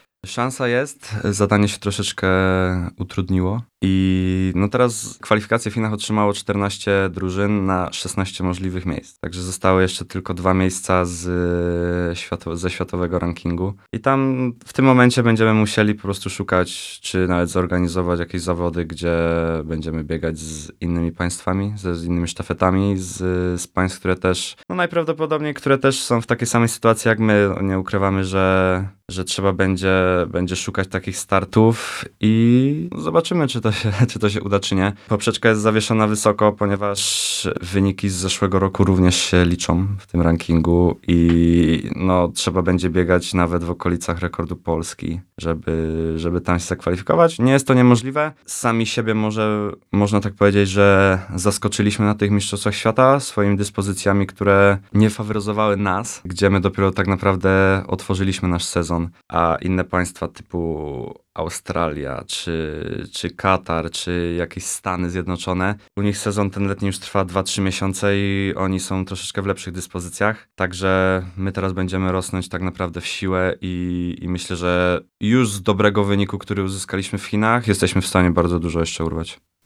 Cała rozmowa w audycji „Sportowy kwadrans” dziś o 15:30!